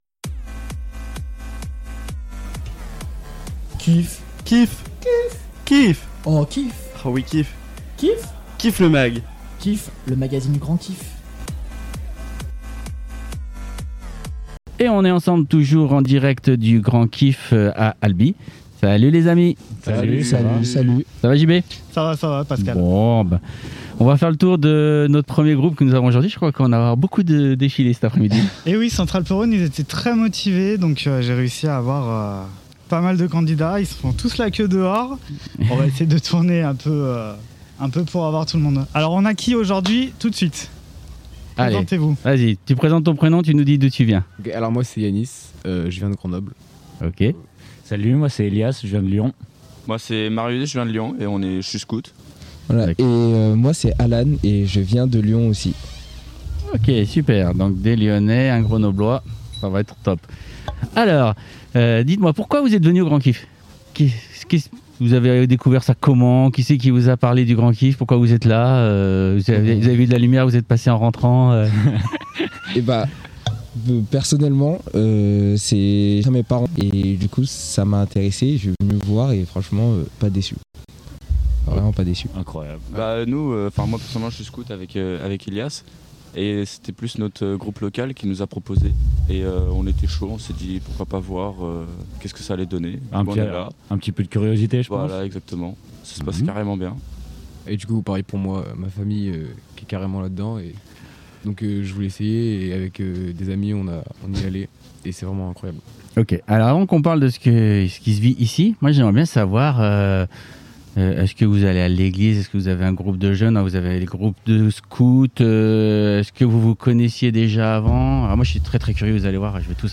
Le 5ème numéro du KIFFMAG en direct de ALBI le 31/07/2021 à 14h00